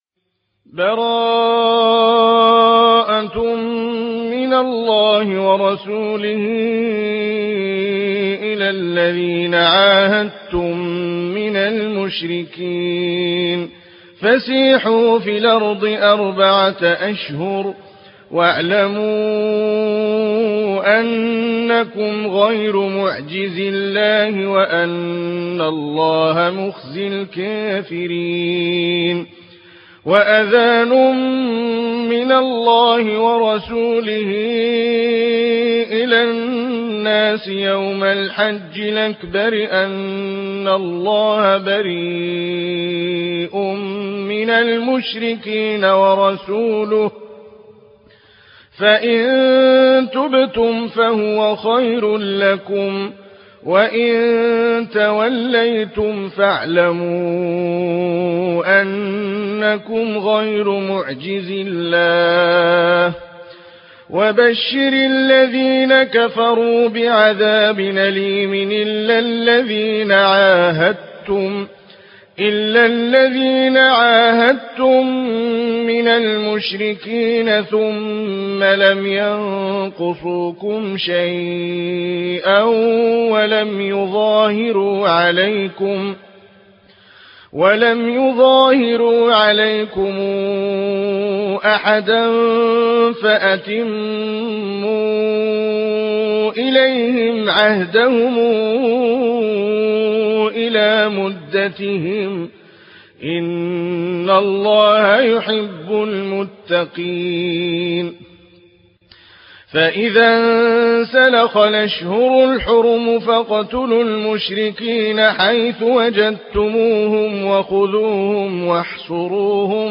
ورش عن نافع